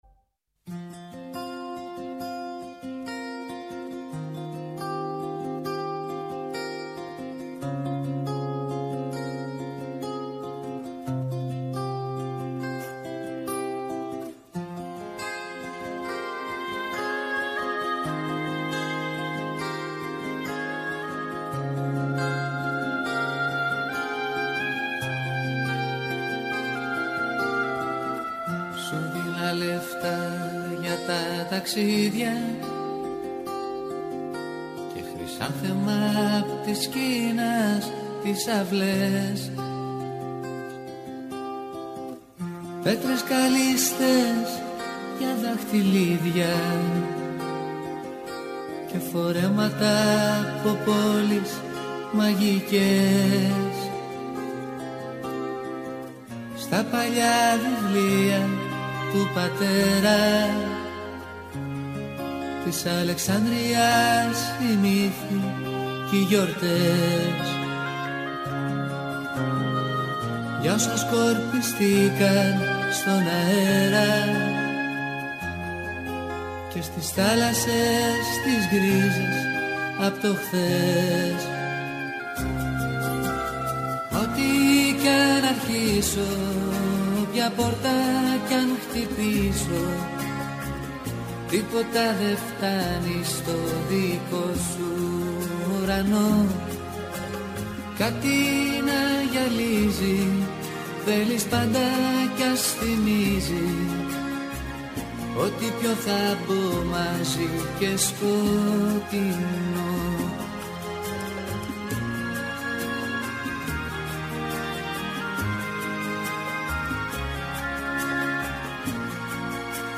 Καλεσμένος στο στούντιο ο συγγραφέας